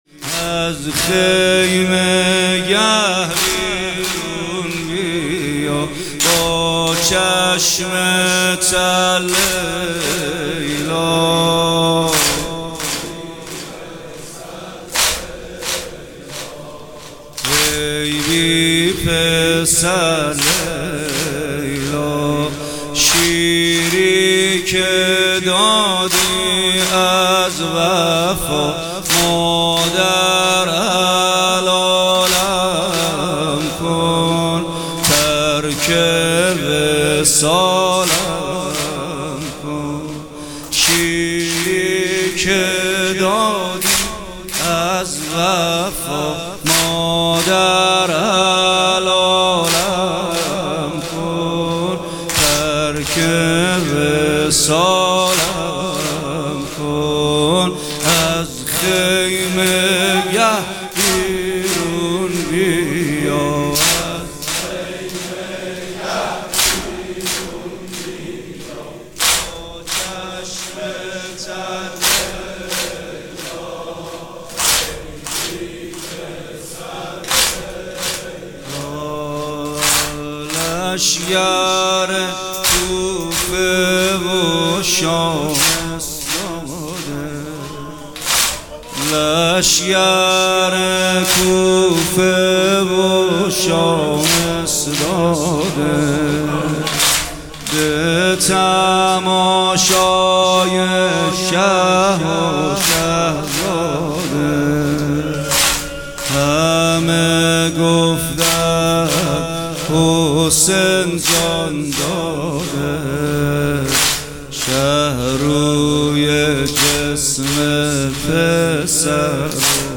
محفل عزاداری شب هشتم محرم ریحانه النبی
در آستان‌ مقدس‌ امامزاده‌ صالح‌ تجریش برگزار شد.